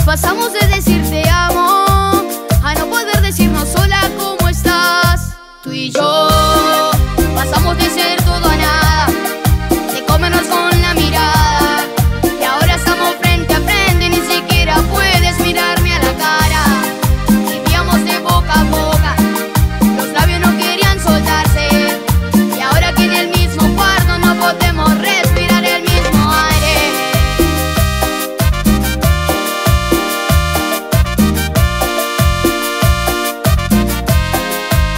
Pop Latino Latin Pop
Жанр: Поп музыка